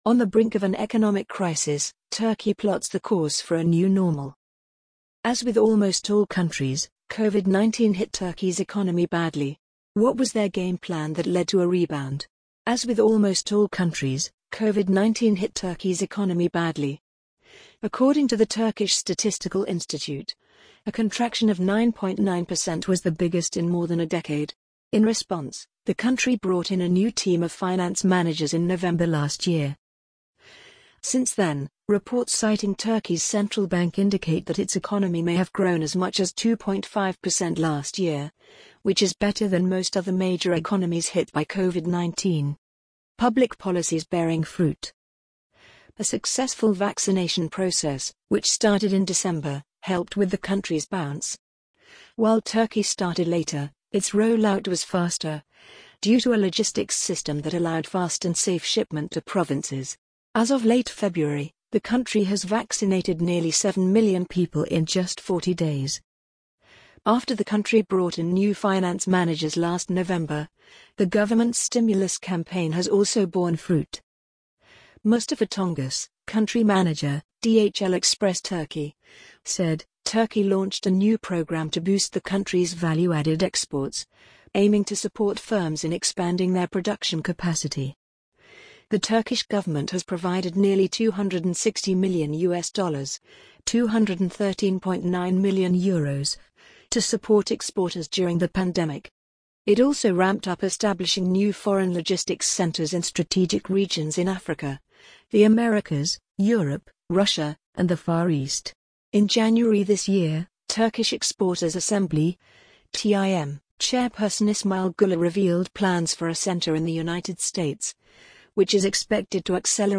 amazon_polly_13460.mp3